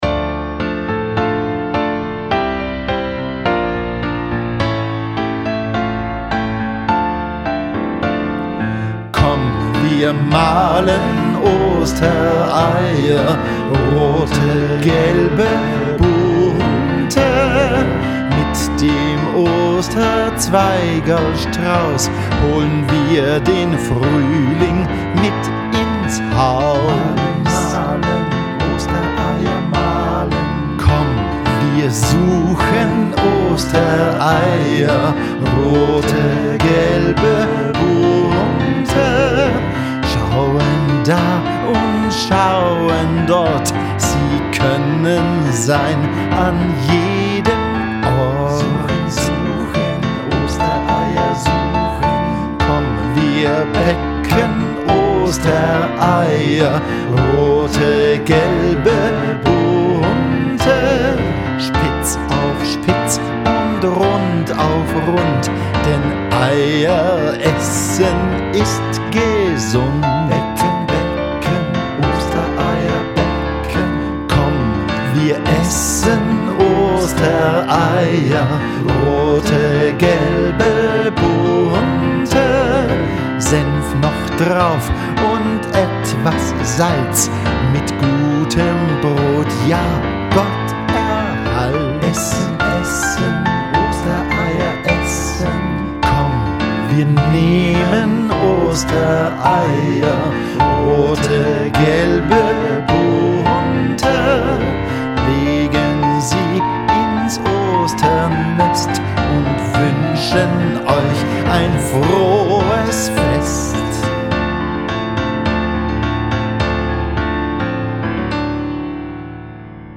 Klavier und Gesang